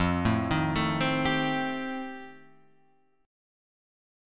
F 9th